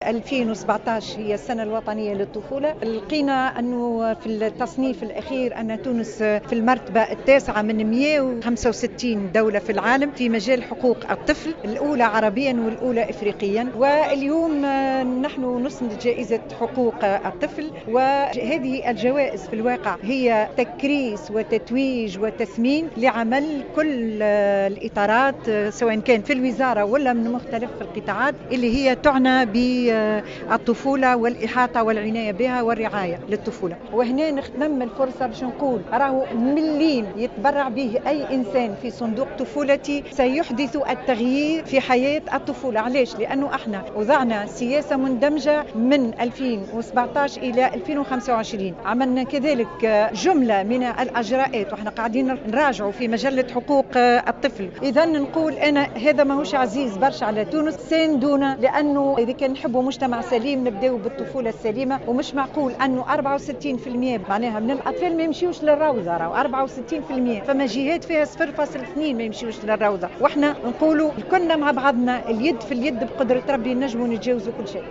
وشدّدت العبيدي في تصريح لمراسلة "الجوهرة أف أم" على هامش حفل اسناد الجائزة الوطنية لحقوق الطفل ووسام الاستحقاق بعنوان قطاع الطفولة لسنة 2016 على أهمية المساهمة في صندوق "طفولتي" التي أحدثته الوزارة بهدف تمويل المشاريع التي تهم الطفولة، خاصة في المناطق ذات الأولوية.